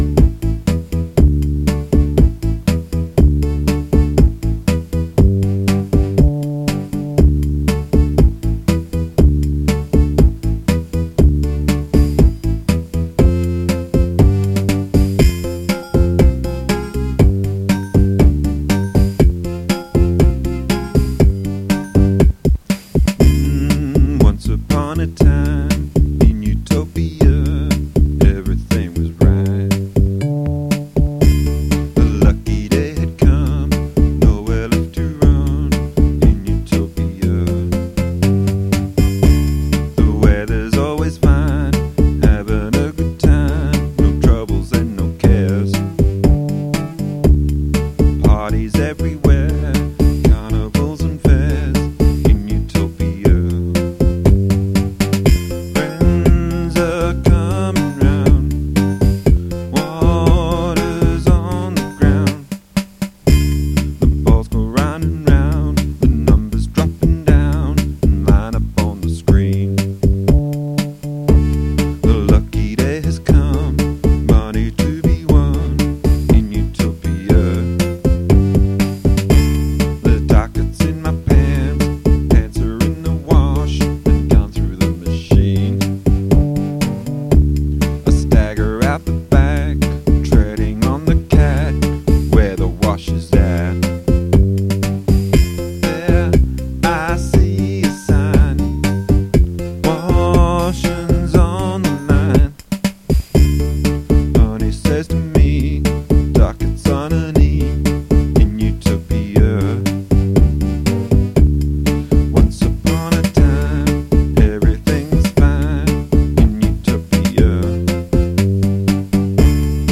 First I use a computer program called Band-in-a-Box.
You pick a style and the software creates the backing, drums, bass and keyboard. This is then recorded onto my Boss 4 track tape deck onto the first track. On the second track some guitar is added by taking a line out of the amp into the 4 track. Vocals are added direct via a microphone, in this case a Shure SM58, straight into the deck. I left the fourth track for a guitar solo but never got around to finishing the project.